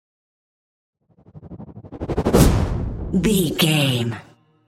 Whoosh to hit trailer
Sound Effects
Atonal
dark
intense
tension
woosh to hit